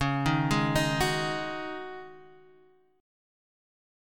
Dbsus2sus4 chord